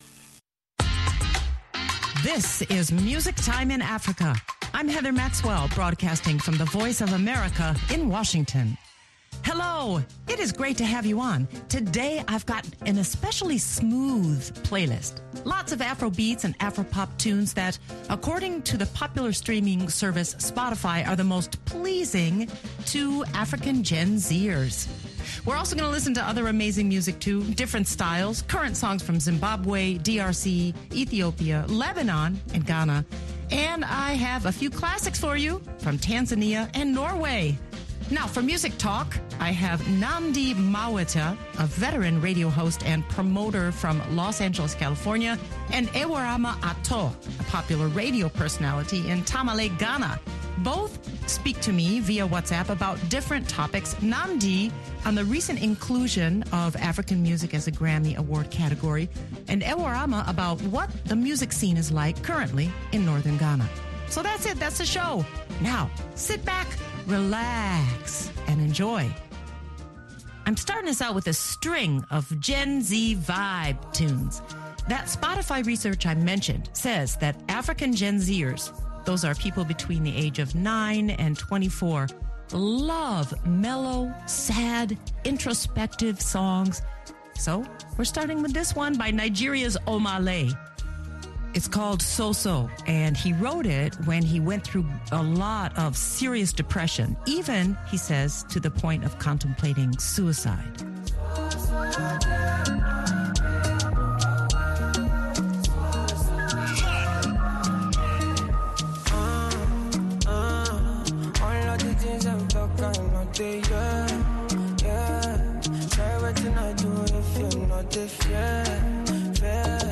The playlist features smooth Afrobeats and Afropop tunes popular among Gen z listeners.